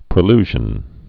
(prĭ-lzhən)